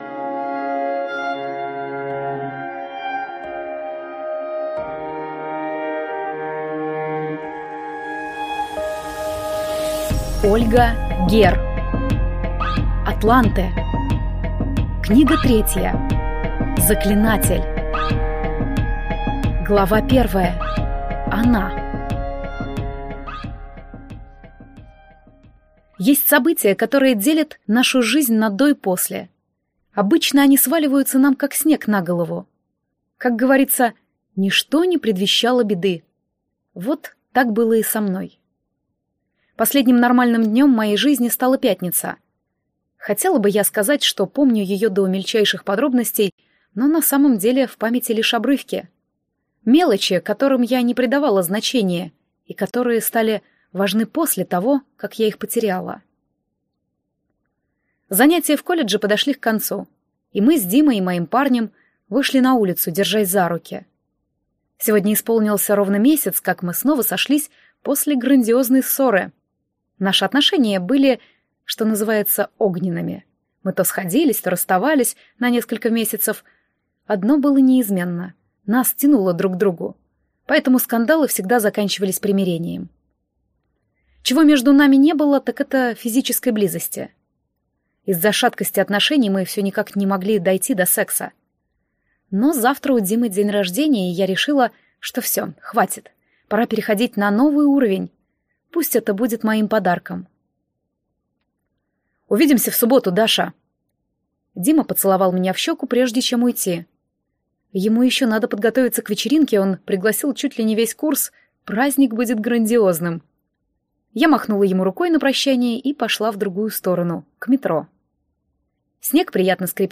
Аудиокнига Заклинатель | Библиотека аудиокниг